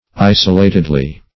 isolatedly - definition of isolatedly - synonyms, pronunciation, spelling from Free Dictionary Search Result for " isolatedly" : The Collaborative International Dictionary of English v.0.48: Isolatedly \I"so*la`ted*ly\, adv.